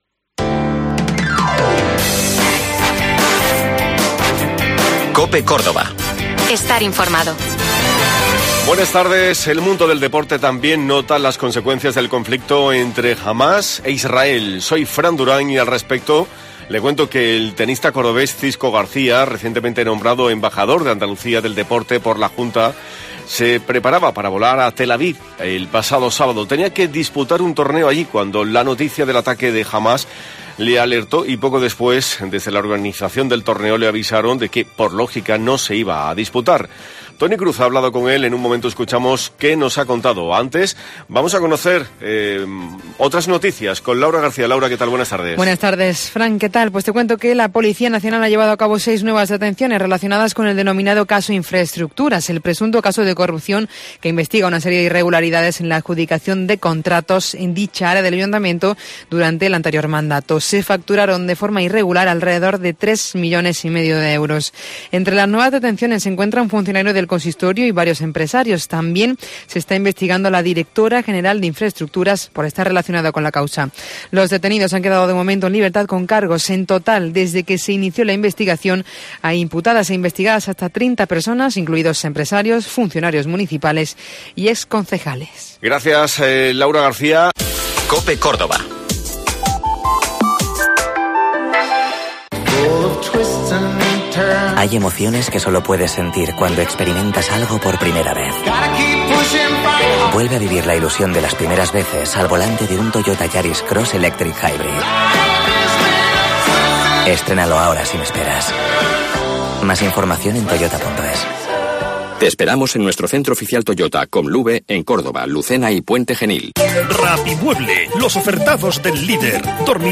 Hemos hablado con el tenista en Herrera en COPE.